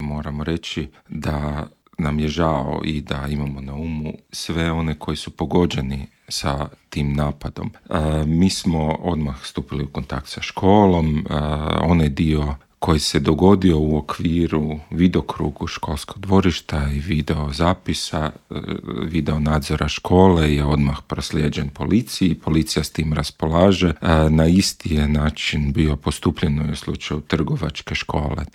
Juroš se u Intervjuu Media servisa osvrnuo i na više slučajeva vršnjačkog nasilja, od događaja ispred osnovne škole u Retkovcu zbog kojeg su prosvjedovali stanovnici Dubrave do slučaja u zagrebačkoj Trgovačkoj školi.